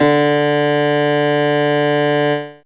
HChordDD3.wav